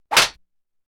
stick trap impact.mp3